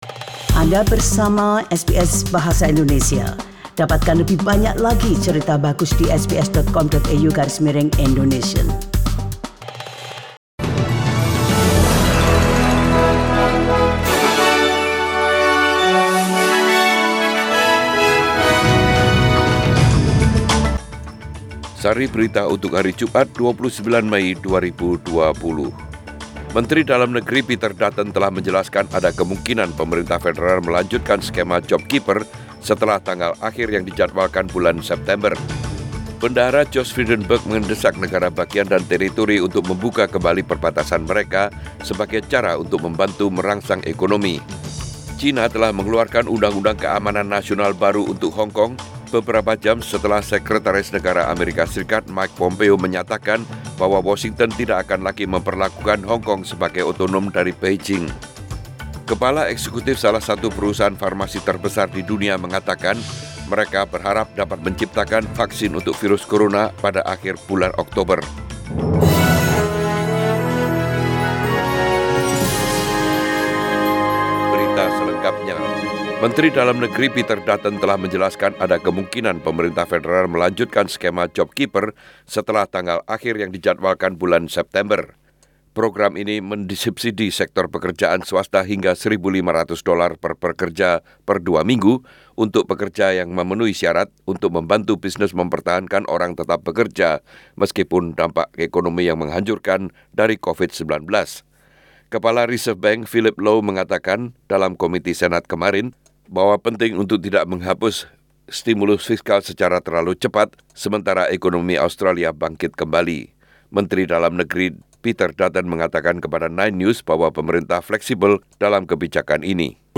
SBS Radio News in Indonesian - 29 May 2020